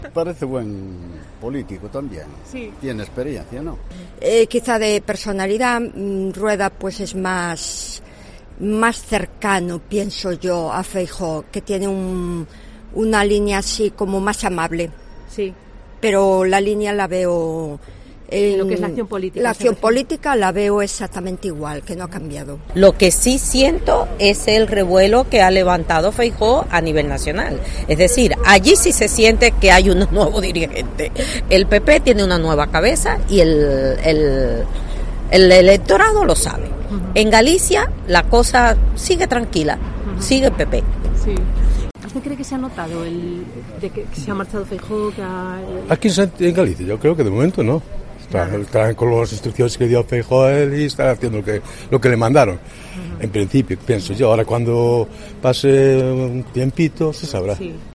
Micrófonos en la calle: ¿Qué tal lo está haciendo Alfonso Rueda como presidente de la Xunta?